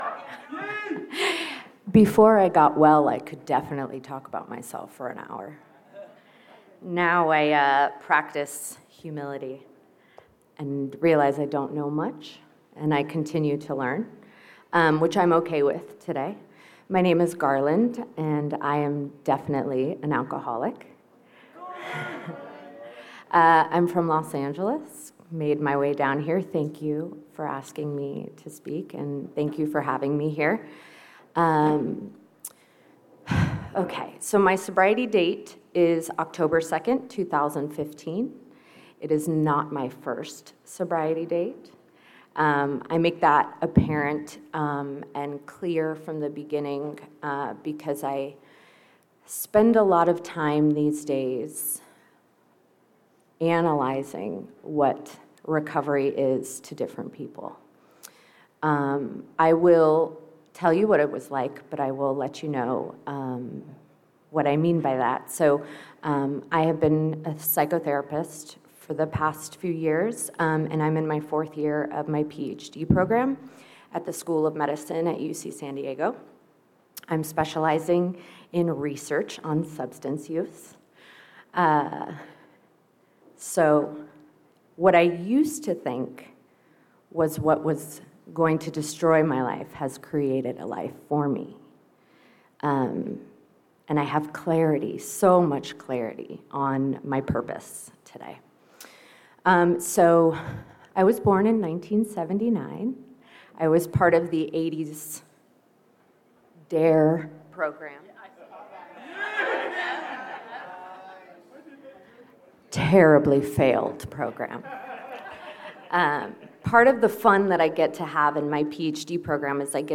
32nd Indian Wells Valley AA Roundup
32nd Indian Wells Valley Roundup